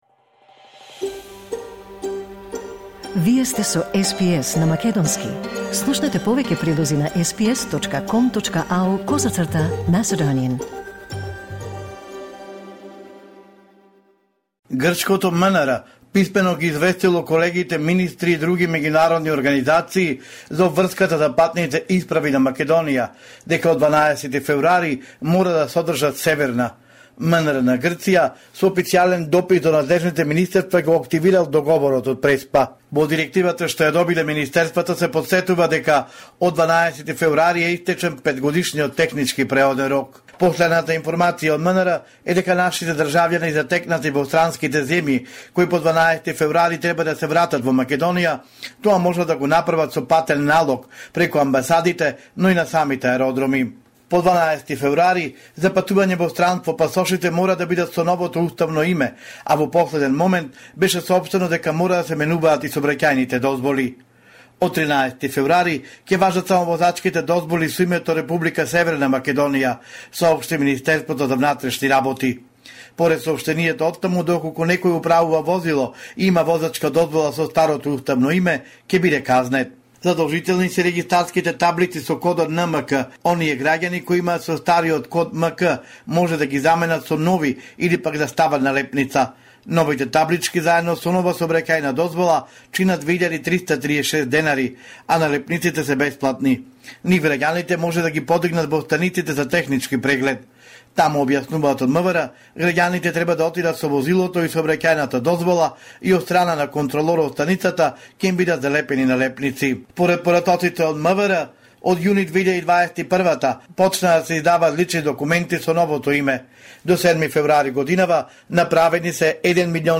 Homeland Report in Macedonian 12 February 2024